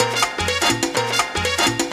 melod_repet_tension.wav